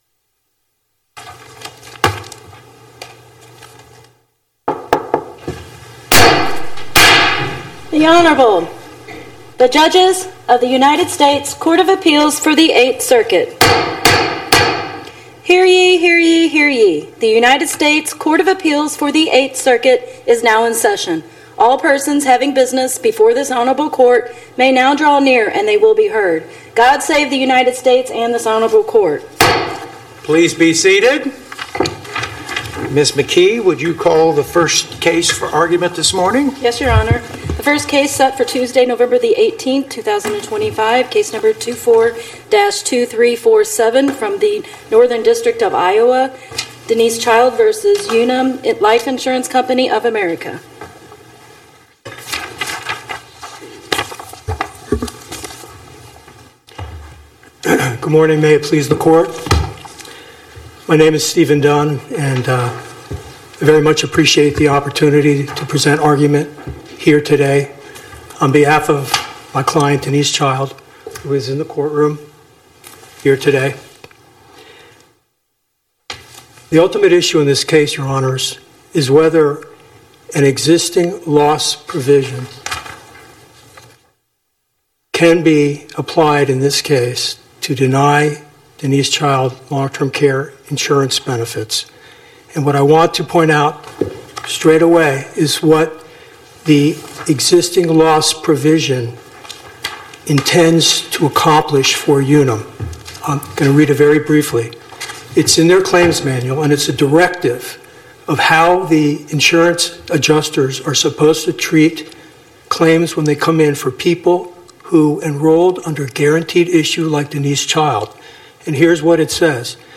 Oral argument argued before the Eighth Circuit U.S. Court of Appeals on or about 11/18/2025